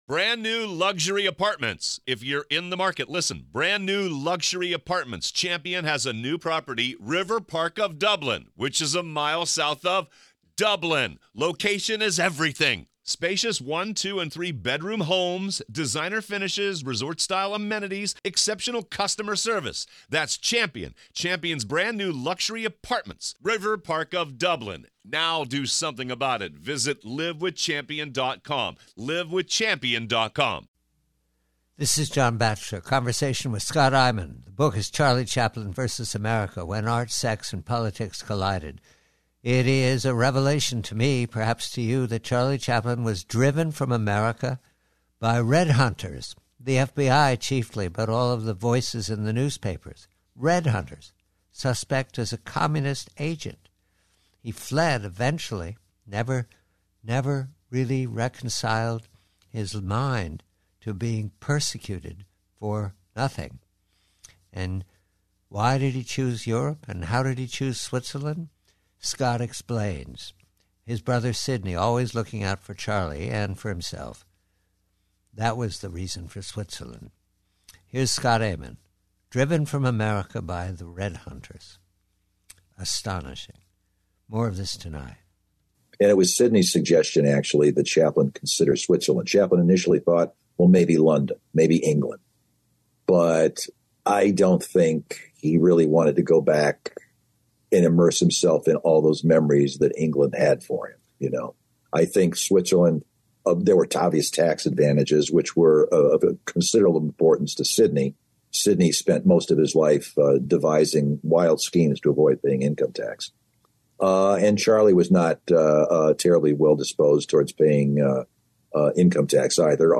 PREVIEW: RED HUNT: Conversation